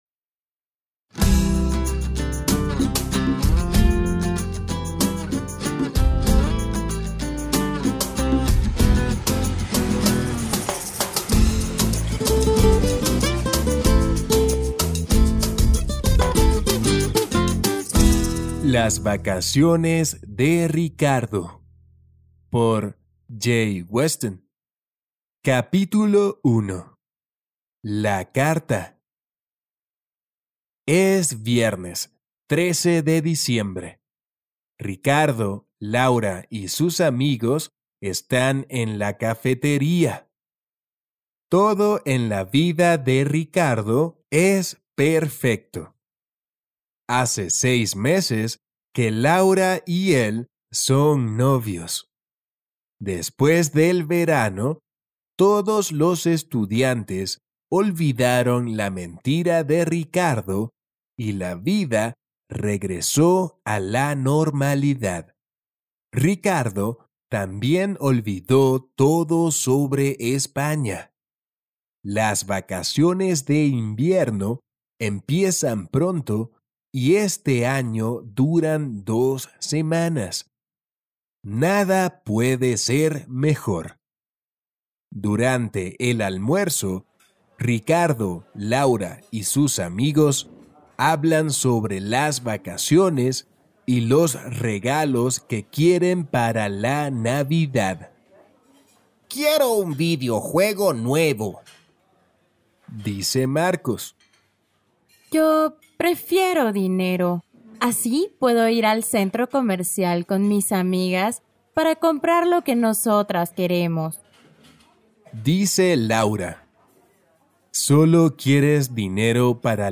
Each audio book contains original music, sound effects and voice acting from Native Spanish speakers to ensure a memorable experience for your students!
Las vacaciones de Ricardo audiobook sample: